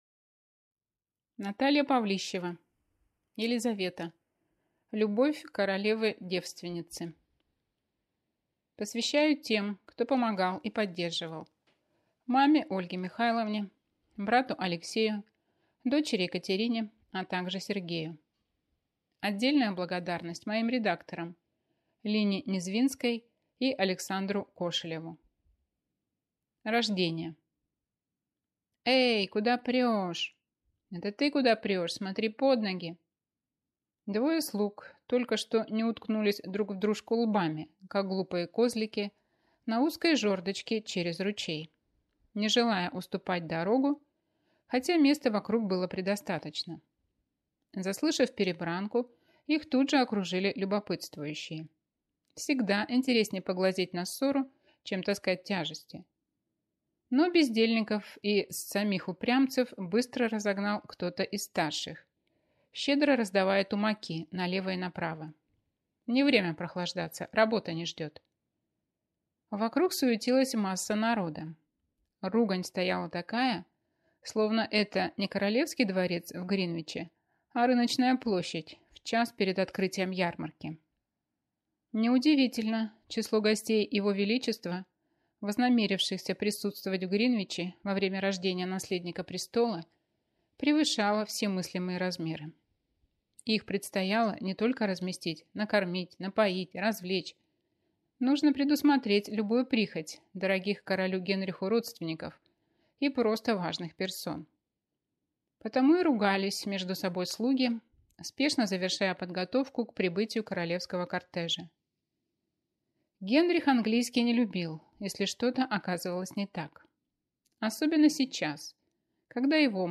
Аудиокнига Елизавета. Любовь Королевы-девственницы | Библиотека аудиокниг